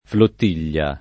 flottiglia [ flott & l’l’a ] s. f.